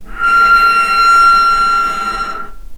vc-E6-mf.AIF